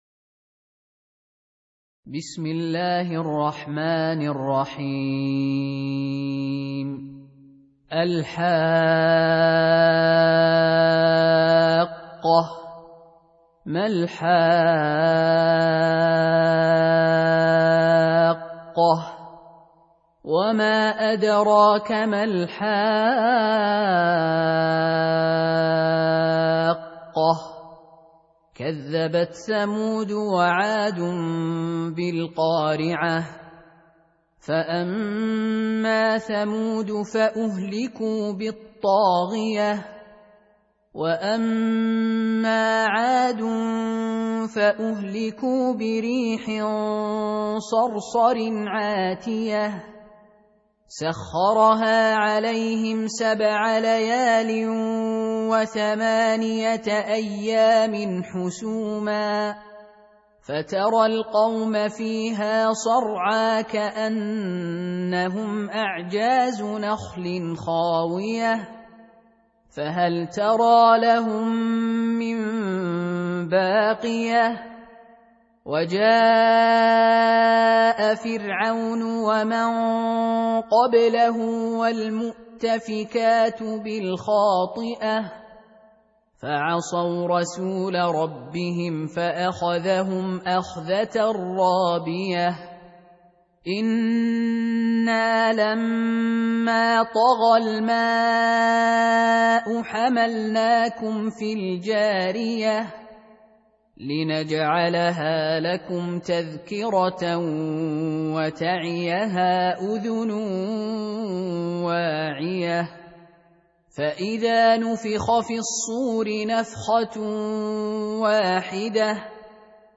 Surah Repeating تكرار السورة Download Surah حمّل السورة Reciting Murattalah Audio for 69. Surah Al-H�qqah سورة الحاقة N.B *Surah Includes Al-Basmalah Reciters Sequents تتابع التلاوات Reciters Repeats تكرار التلاوات